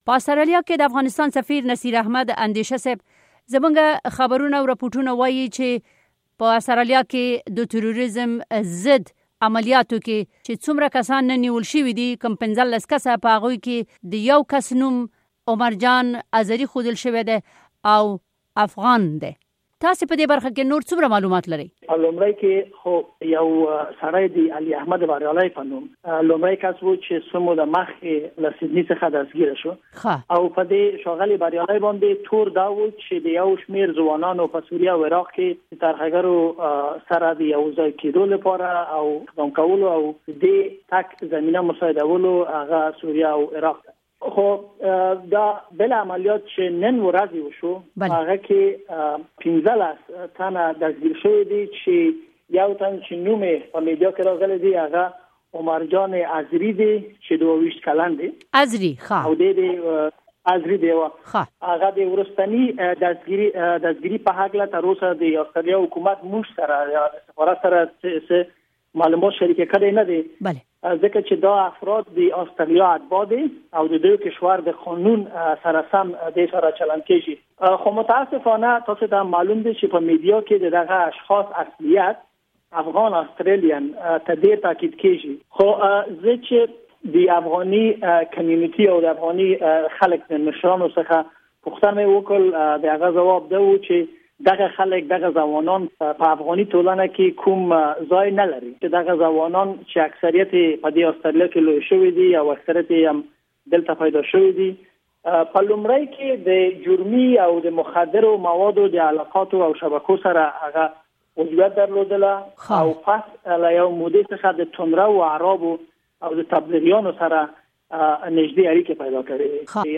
په استرالیا کې د افغاسنتان د سفیر سره مرکه